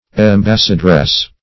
Embassadress \Em*bas"sa*dress\, n.